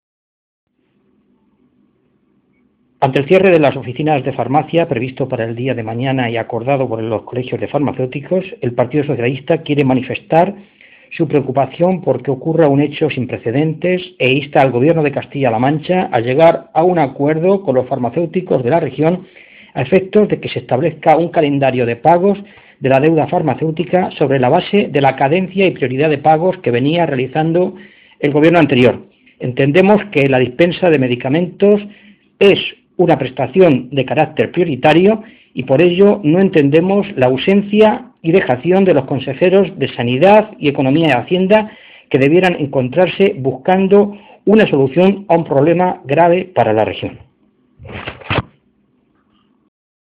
Fernando Mora, diputado regional del PSOE de C-LM
Cortes de audio de la rueda de prensa